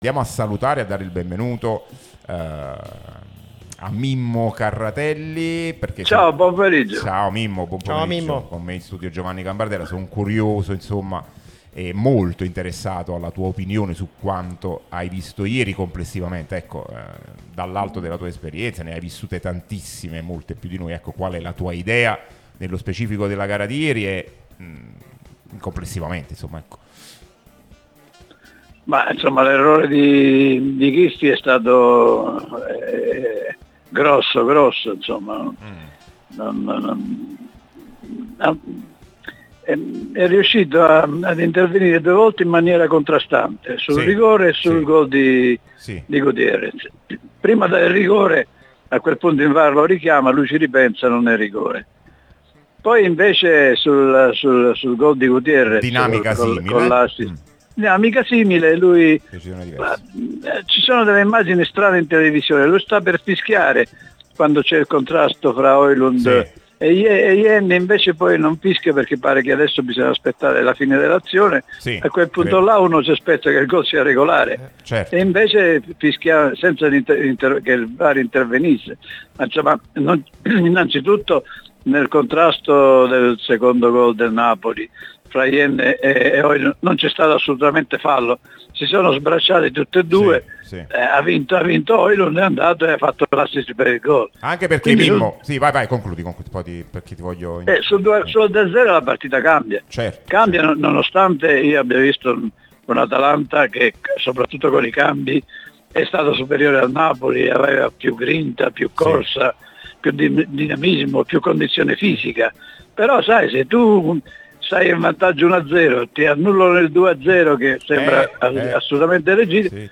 trasmissione sulla nostra Radio Tutto Napoli, prima radio tematica sul...